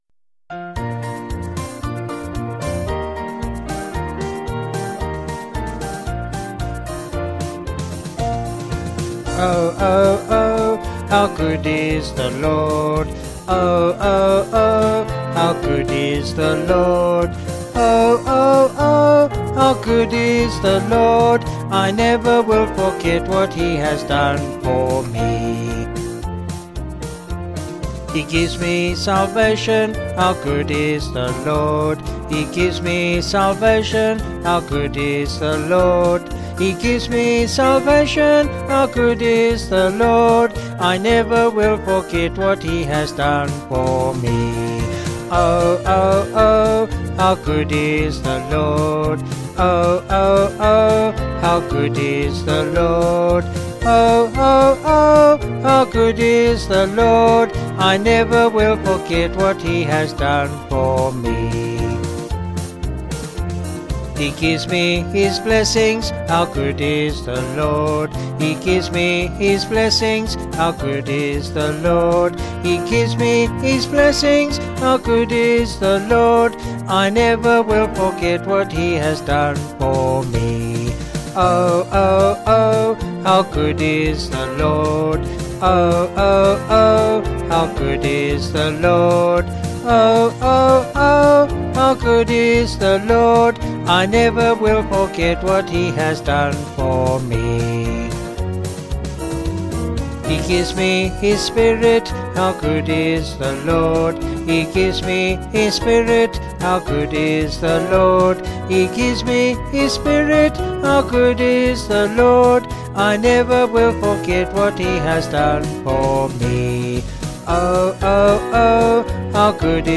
Vocals and Band   261.4kb